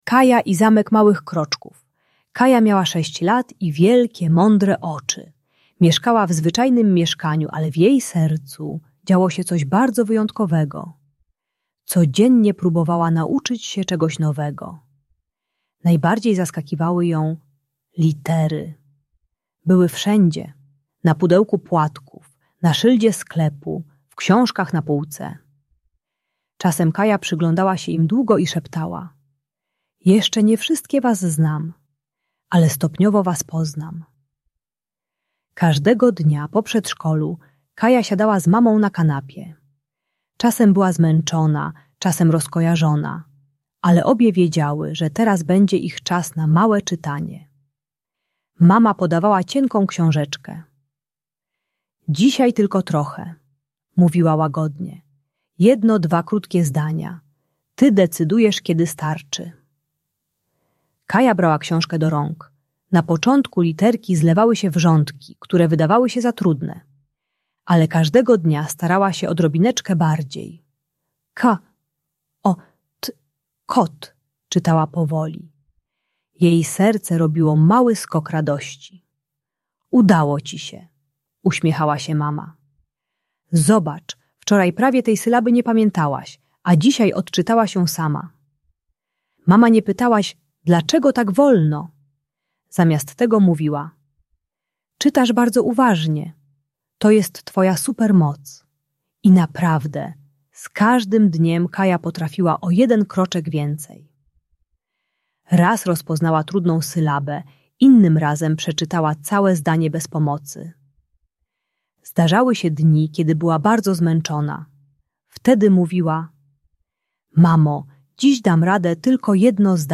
Pomaga dzieciom zrozumieć, że wolne tempo nauki jest wartościowe i uczy techniki "małych kroczków" - codziennego ćwiczenia bez presji. Idealna audiobajka motywacyjna dla dziecka które nie chce się uczyć lub szybko się zniechęca przy nauce czytania.